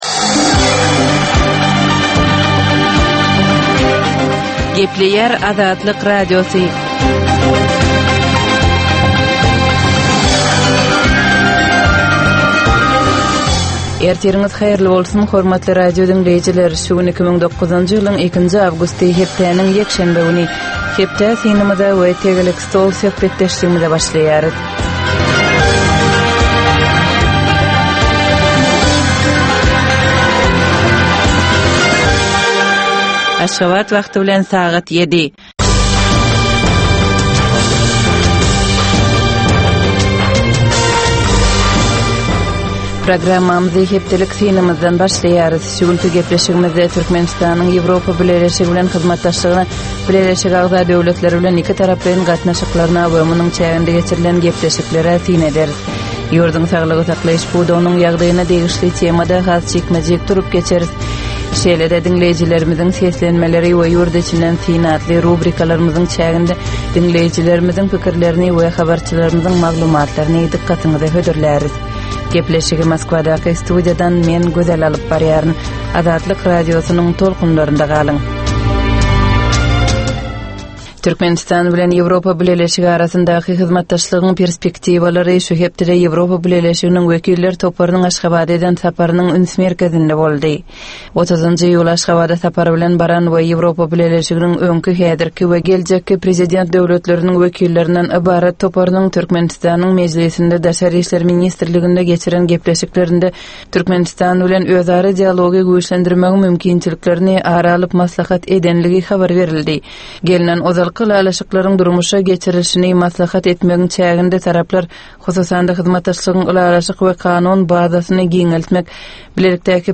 Tutus geçen bir hepdänin dowamynda Türkmenistanda we halkara arenasynda bolup geçen möhüm wakalara syn. 30 minutlyk bu ýörite programmanyn dowamynda hepdänin möhüm wakalary barada gysga synlar, analizler, makalalar, reportažlar, söhbetdeslikler we kommentariýalar berilýar.